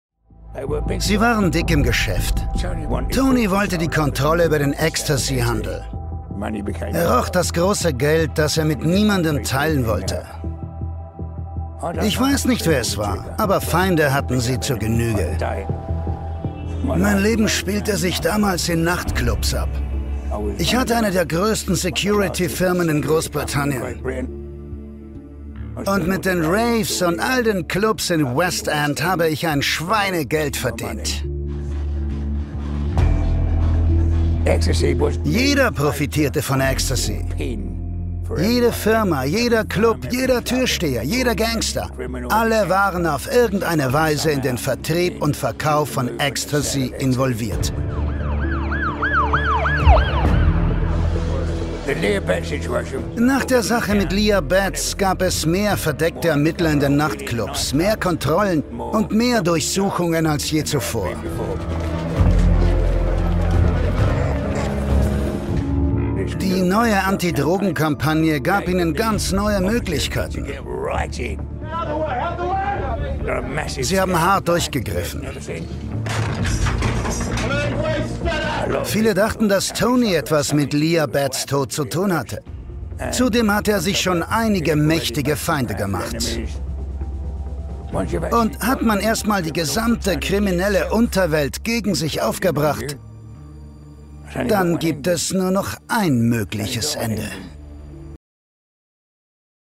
REFERENZEN ll ausgebildeteter Schauspieler mit 40 Jahren Berufserfahrung: TV / Film / Serie / Theater / Werbung / Synchron Kraftvolle, sonore, lebendige, warme Stimme für: Werbung-druckvoll- Dokumentationen / off voice / Telefonsysteme / Guides ( Museum, Stadtführungen) Hörbuch, Hörspiele / Radiogeschichten / Lyrik / Lesungen Einkaufsradio / Dokusoap / Kinowerbung / Messen Trickstimmen TV-Sende: Arte, SWR, ORF Werbung: Einkaufsradio ( Hauptsprecher), Spots, Off Voice, Synchron, Dokumentation, PC- Games: Lesungen, Moderationen usw. viel, viel für arte Trickstimme ( Kinderpingui ) und vieles mehr Hörbücher, Kinderliteratur.
Sprechprobe: eLearning (Muttersprache):